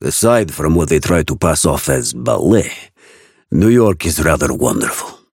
Raven voice line - Aside from what they try to pass off as "ballet", New York is rather wonderful.